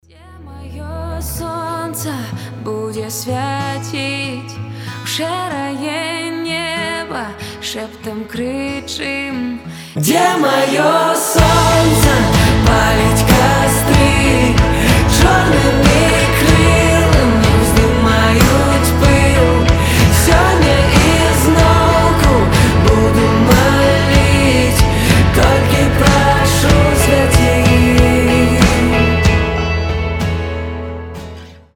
• Качество: 320, Stereo
красивые
дуэт
indie pop